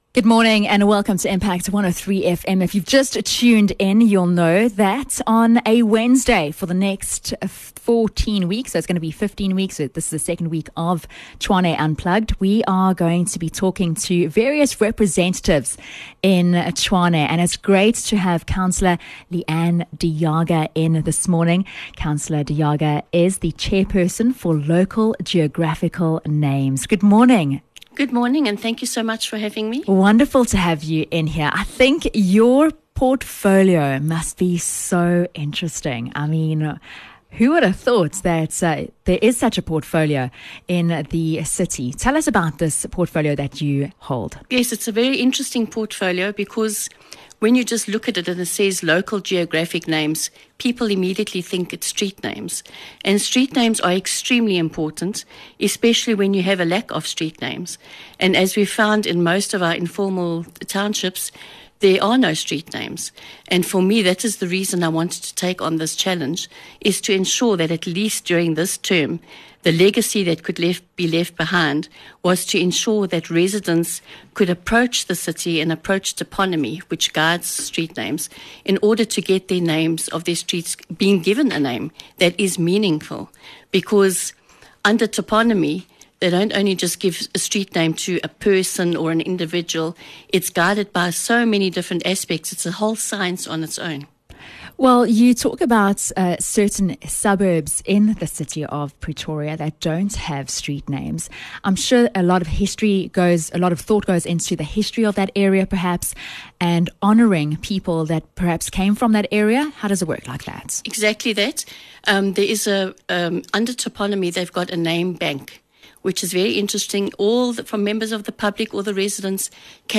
Interview with Chairperson: Cllr L De Jager - Local Geographical Names – 14 September 2022